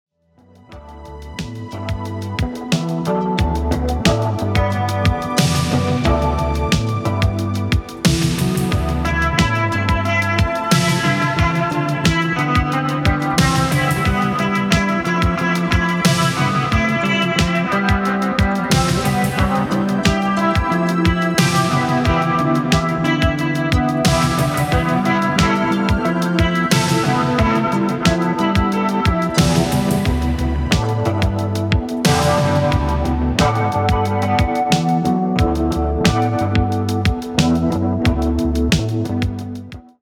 全編に渡って脱力感の漂うベッドルーム・ポップ/シンセ・ファンク/ダウンテンポetcを展開しています。